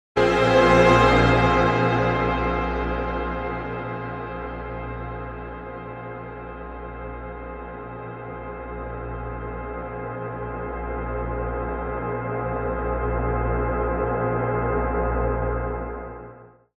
Audacity’s built-in reverb can make pretty long tails if you use extreme settings (see below).
Here’s a “before and after” with the settings shown: